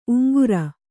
♪ uŋgura